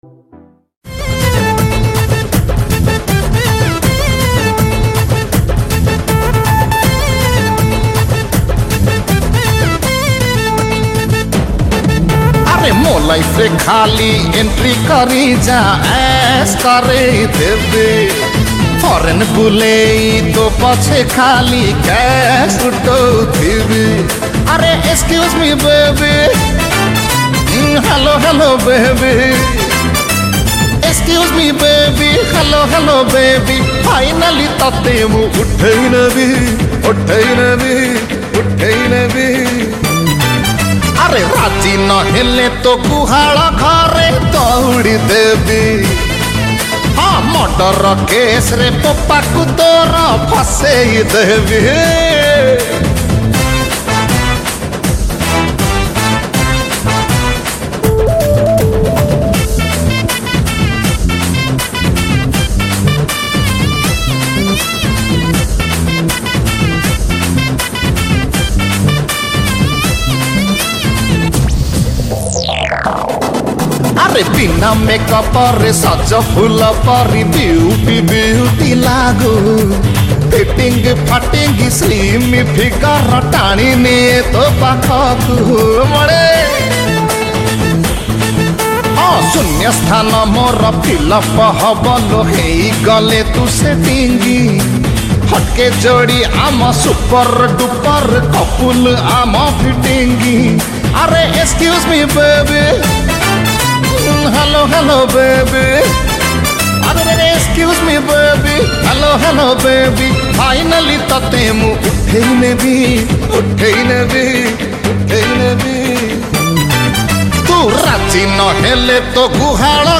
Odia Dance Song